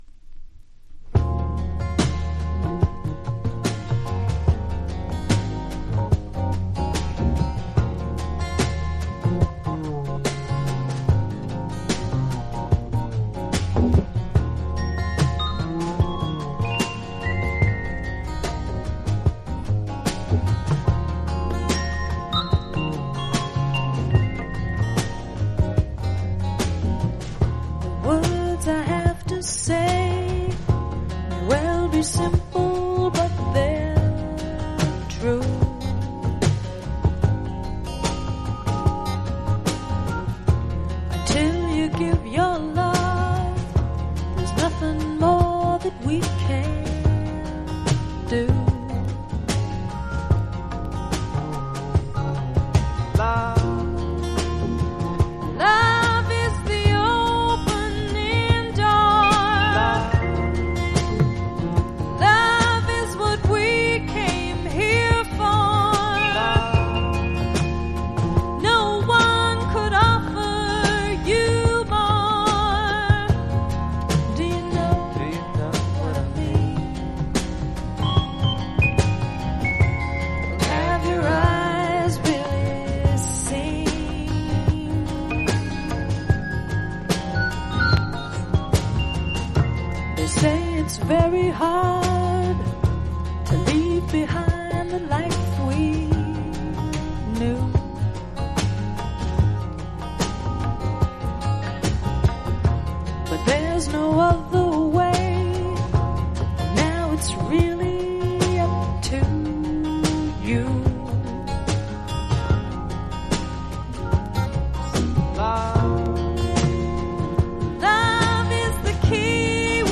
（MONO針で聴くとほとんどノイズでません）※曲名をクリックする…